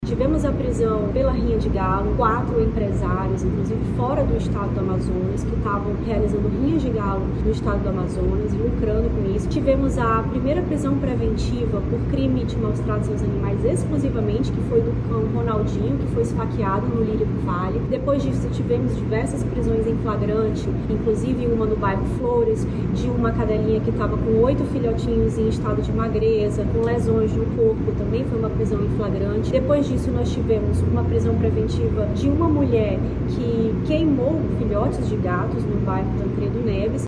A delegada destaca, ainda, alguns casos que tiveram forte repercussão, na cidade de Manaus, em 2024.